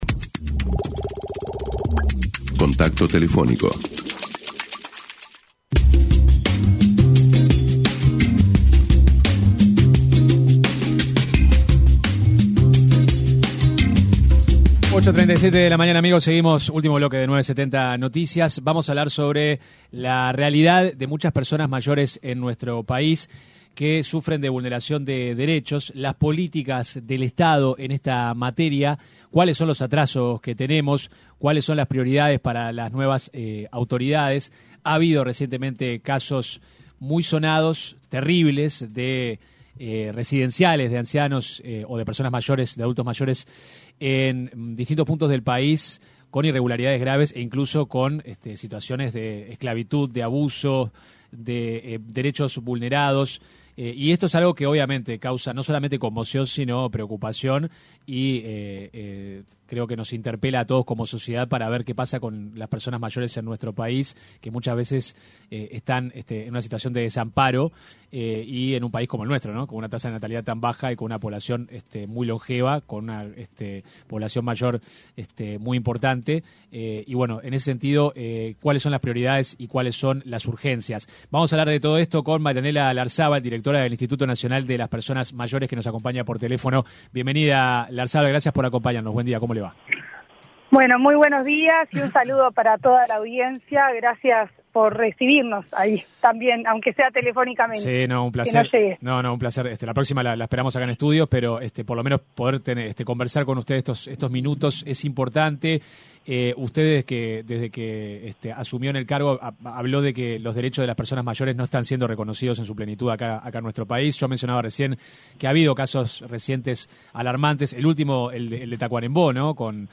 Escuche la entrevista completa aquí: La directora de Inmayores, Marianela Larzabal, se refirió en diálogo con 970 Noticias, a los centros clandestinos que atienden a personas mayores.